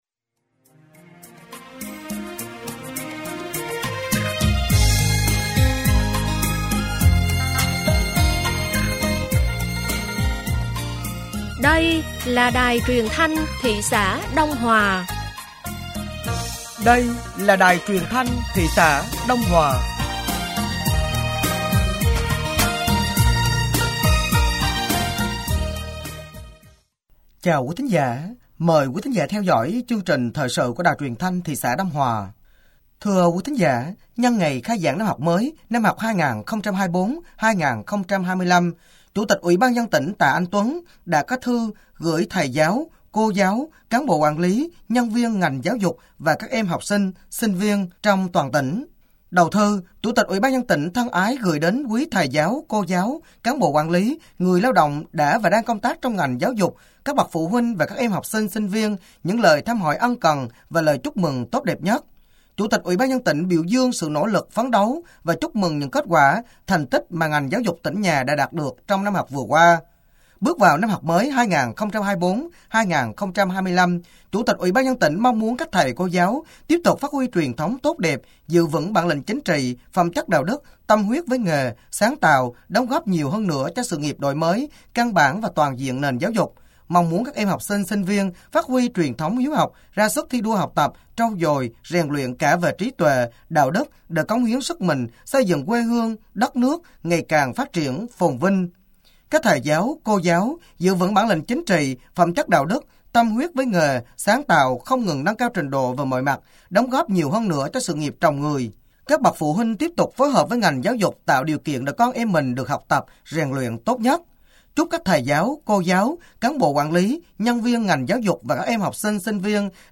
Thời sự tối ngày 05 và sáng ngày 06 tháng 9 nămn 2023